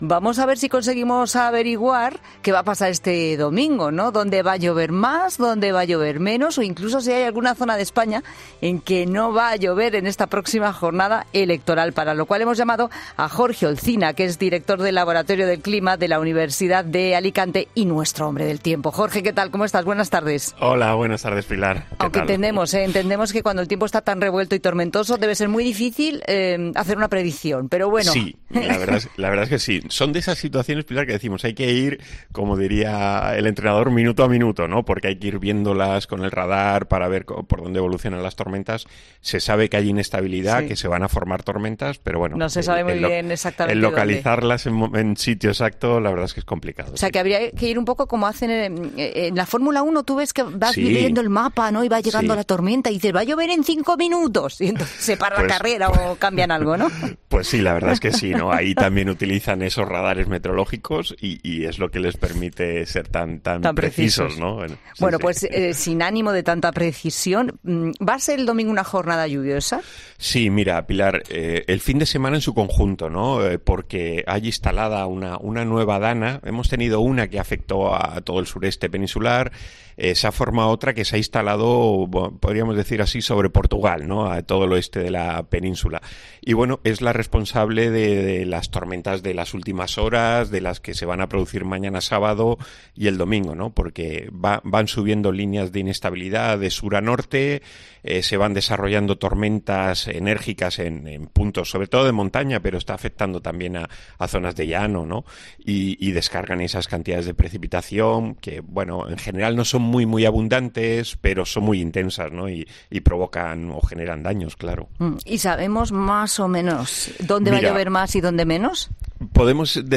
El experto en climatología explica en La Tarde por qué, a pesar de que vienen jornadas de lluvia, no ayudará a frenar el problema de la sequía